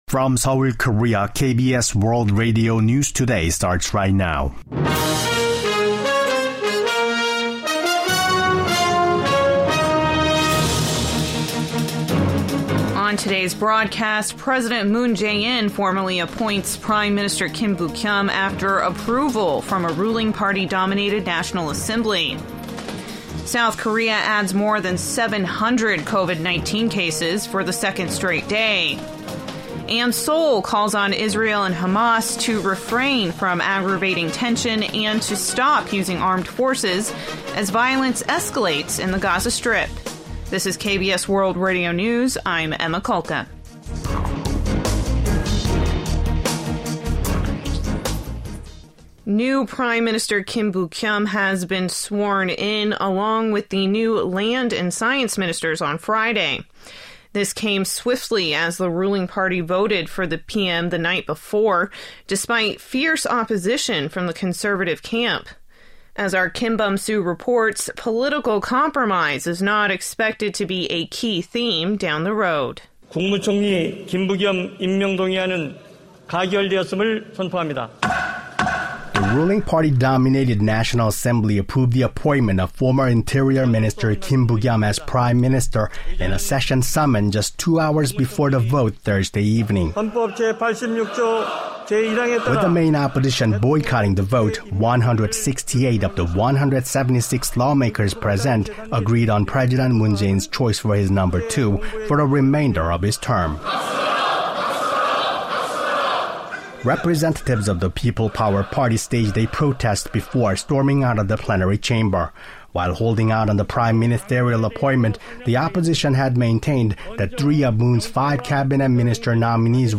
The latest news from home and abroad, with a close eye on Northeast Asia and the Korean Peninsula in particular … continue reading 524 episod # South Korea # KBS WORLD Radio # International News # News